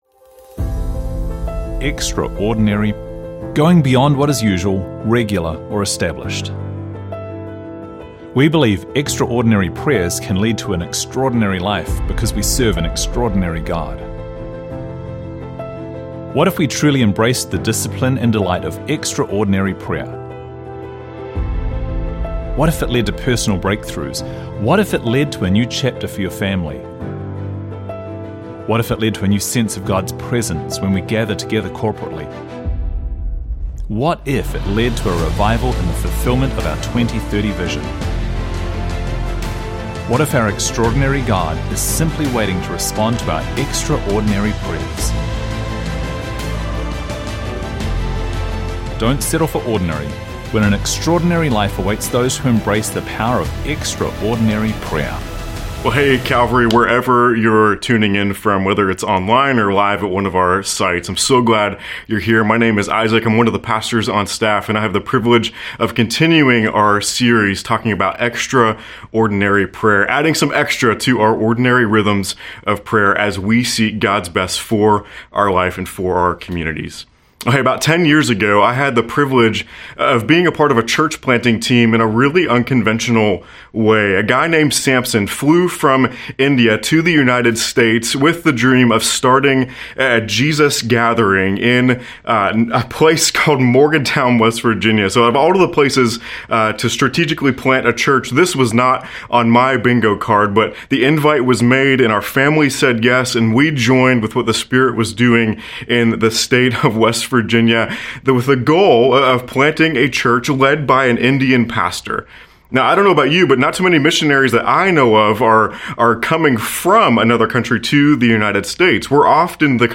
The sermon emphasizes the importance of not only speaking to God in prayer but also actively listening to His voice. It unpacks how Gods guidance is present not just in significant moments but also in the everyday, seemingly mundane aspects of life.